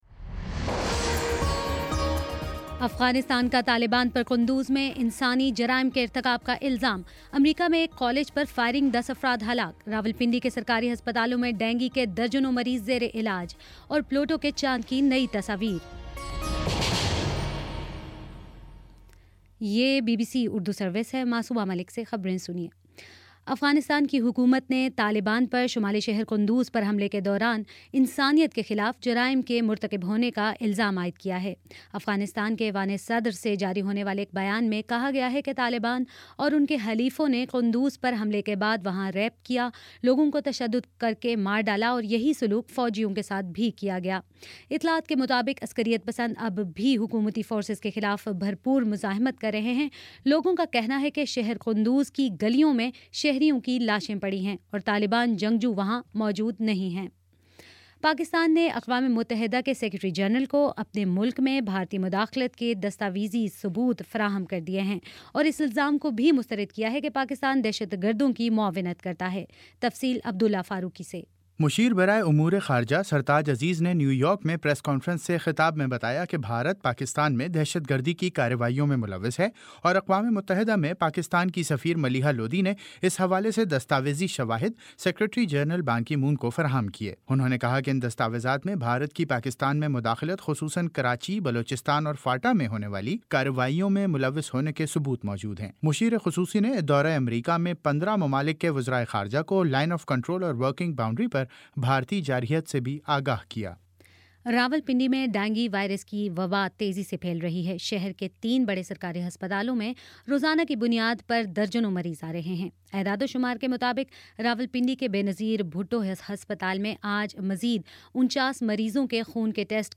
اکتوبر 02 : شام چھ بجے کا نیوز بُلیٹن